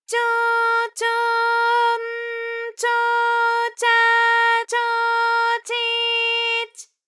ALYS-DB-001-JPN - First Japanese UTAU vocal library of ALYS.
cho_cho_n_cho_cha_cho_chi_ch.wav